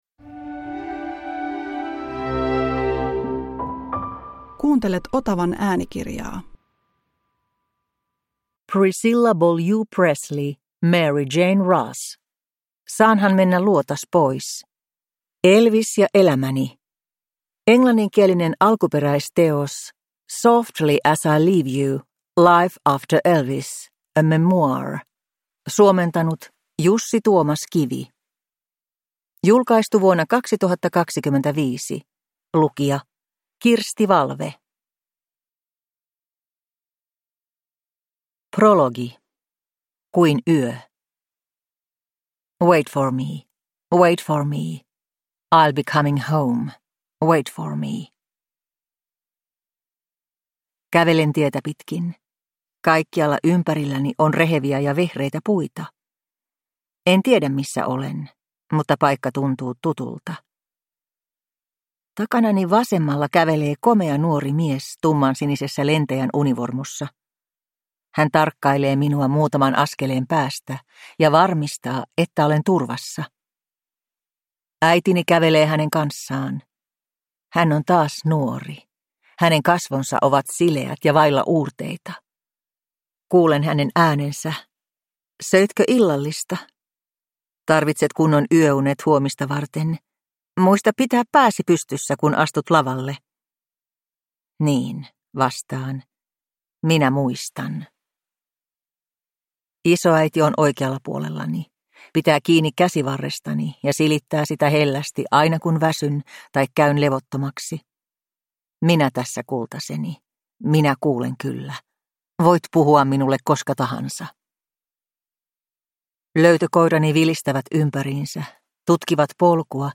Saanhan mennä luotas pois – Ljudbok